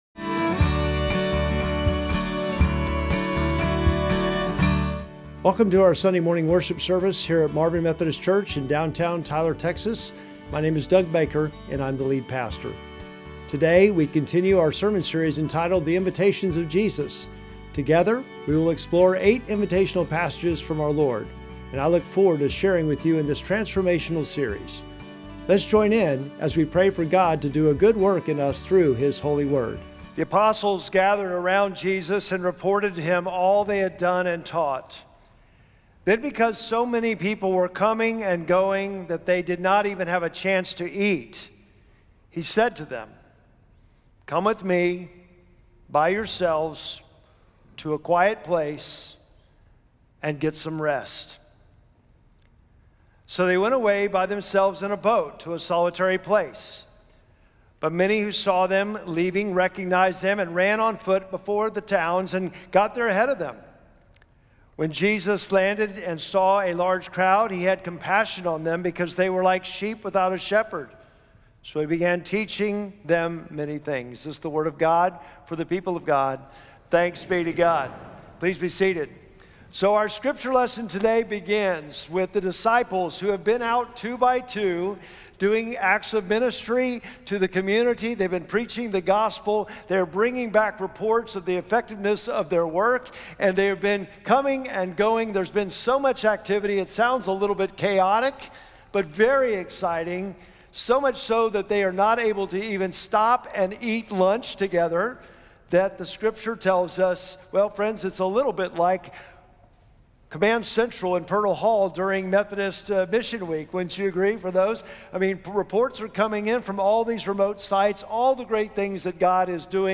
Sermon text: Mark 6:30-34